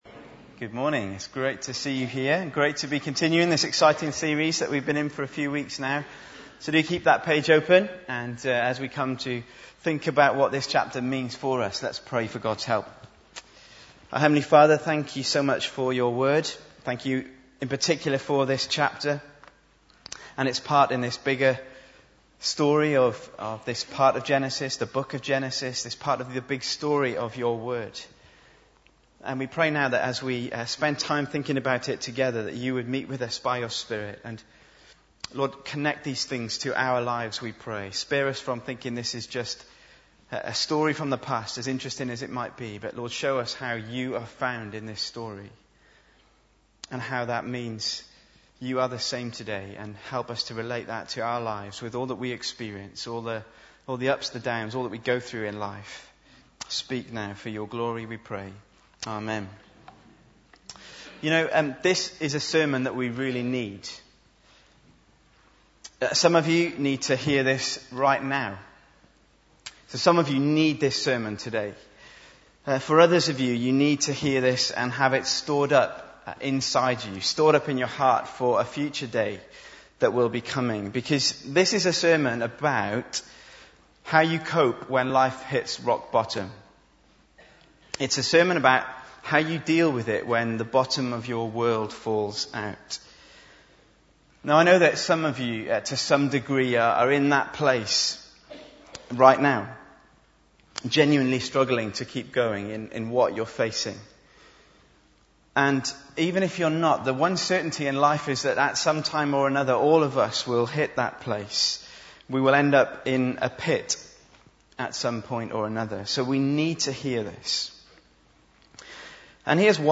Bible Text: Genesis 39:21-40:23 | Preacher